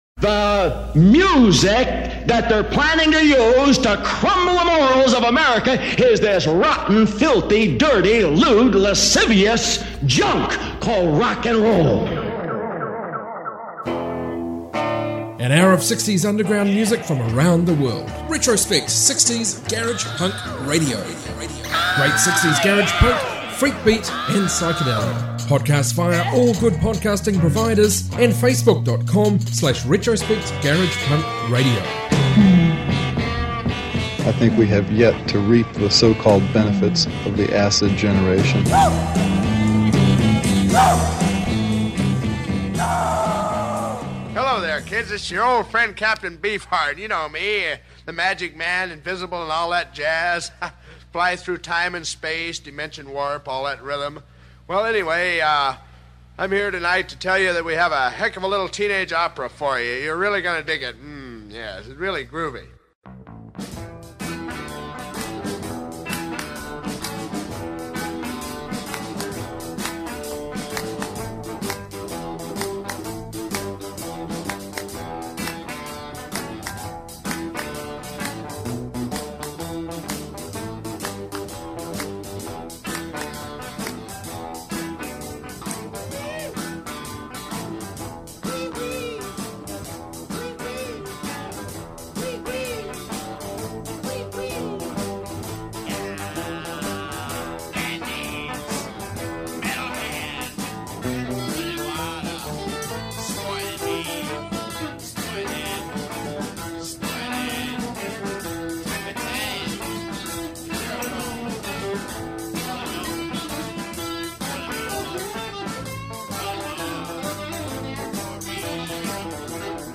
60s garage punk, freakbeat and psych